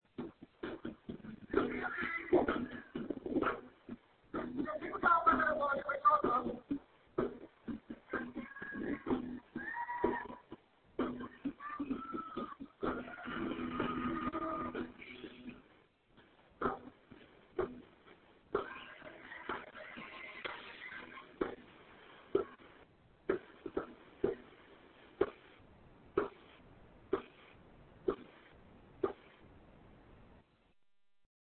Go, Johnny, go go go! Download My cell phone couldn't capture the glory of the noise (and even I can't tell what I say at the beginning). As runners flowed by, hundreds of fans per block cheered, and a rock band played "Johnny B. Goode" on the steps of a bar.